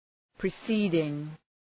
Προφορά
{prı’si:dıŋ}